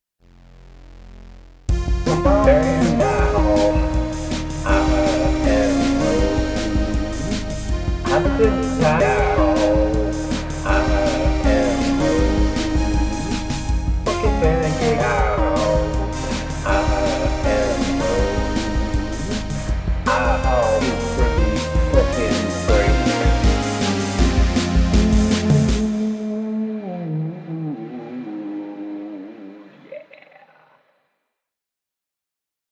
Sung, not read.